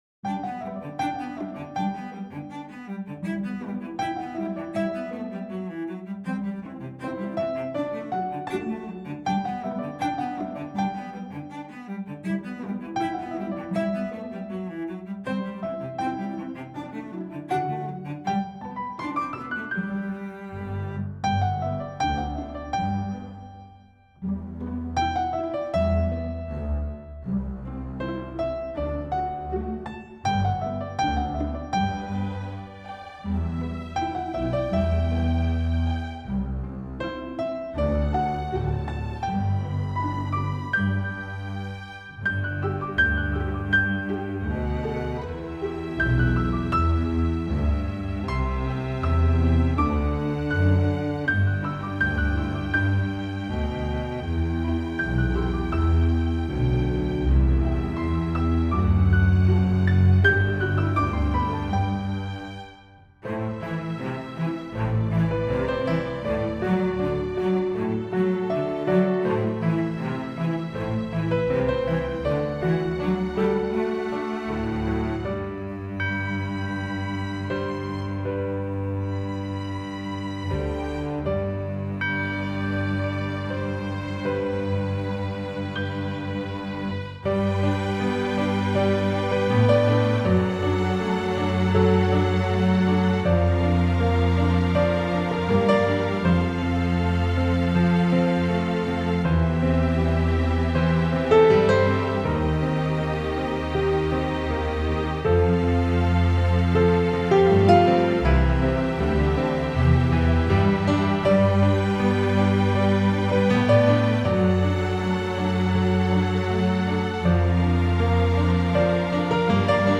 Genre: Documentary Underscore.